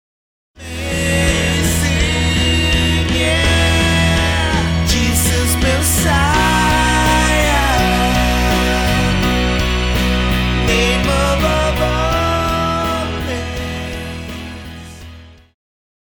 Classical
Vocal - female,Vocal - male
Band
POP,Christian Music
Instrumental
Solo with accompaniment